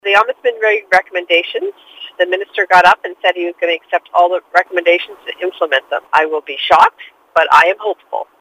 When the report was presented, Scott explains the Minister’s response: